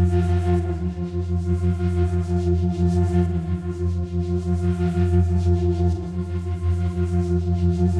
Index of /musicradar/dystopian-drone-samples/Tempo Loops/90bpm
DD_TempoDroneB_90-F.wav